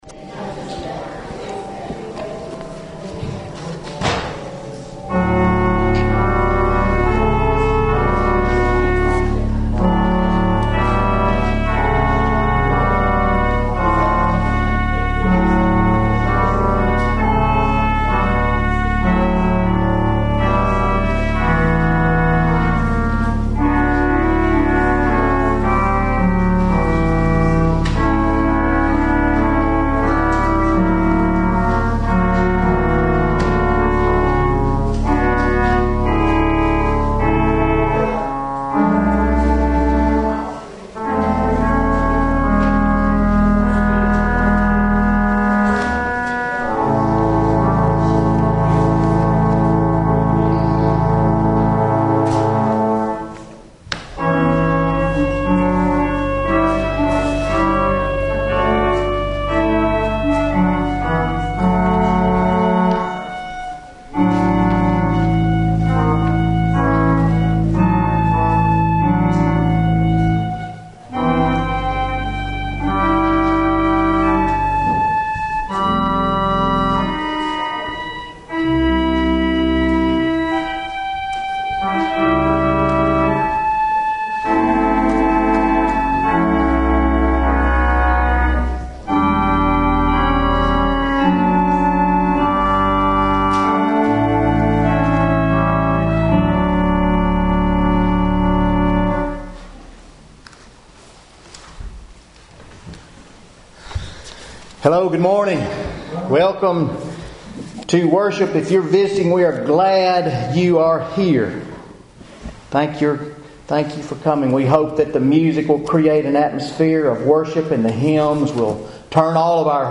November 18, 2018 Worship Service Matthew 4:23-25